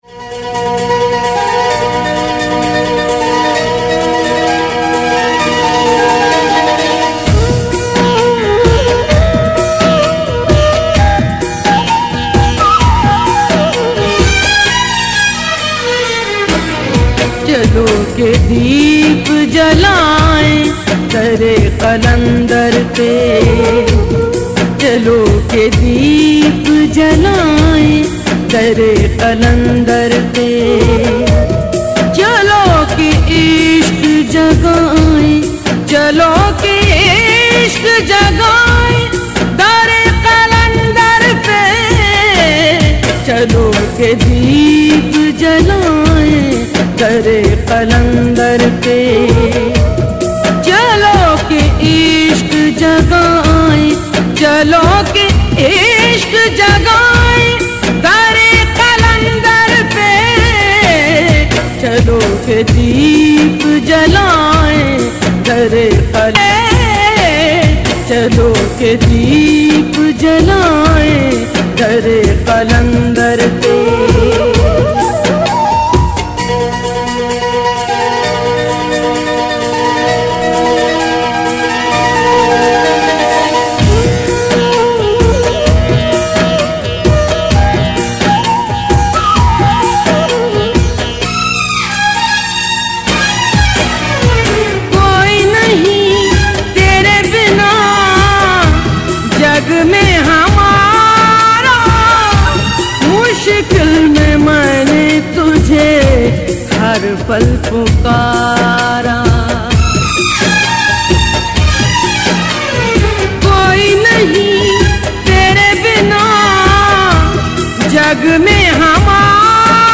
Kalaam/Poetry ,ﺍﺭﺩﻭ urdu , ﭙﻨﺠﺎﺑﻰ punjabi
devotional Sufi song